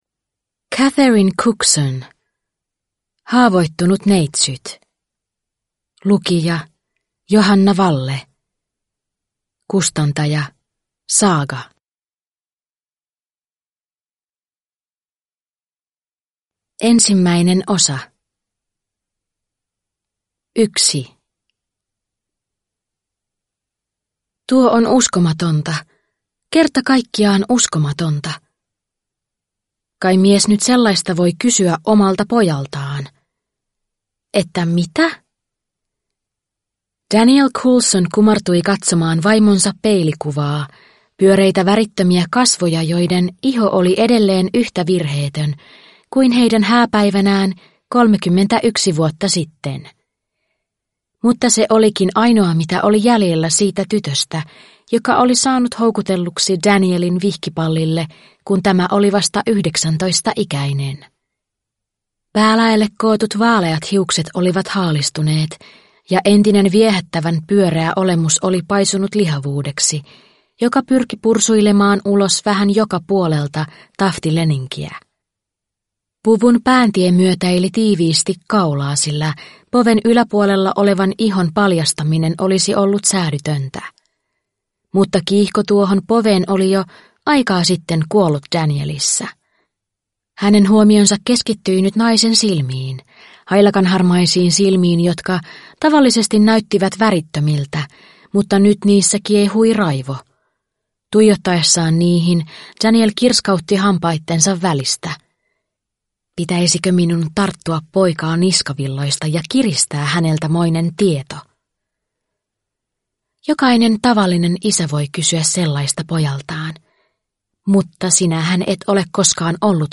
Haavoittunut neitsyt (ljudbok) av Catherine Cookson